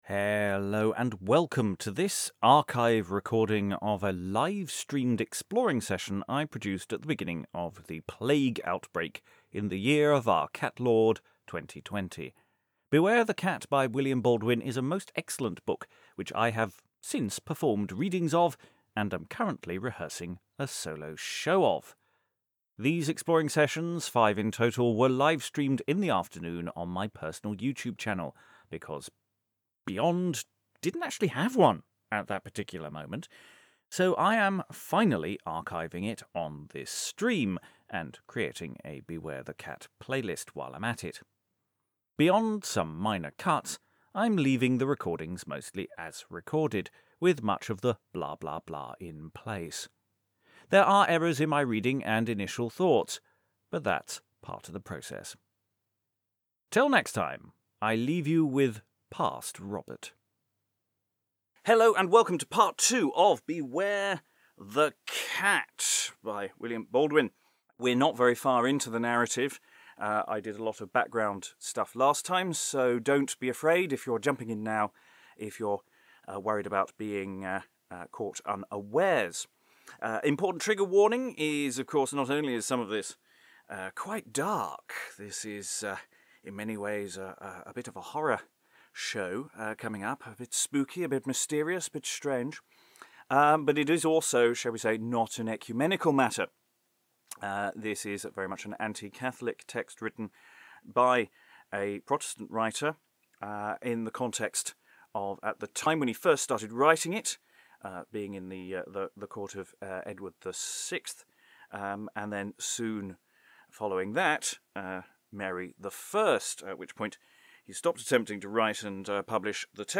More archival exploring sessions on Beware the Cat...
It's a First Look, so it's rough as such things are, and obviously we've come a long way since then. This second part begins midway through the First Oration of Master Streamer.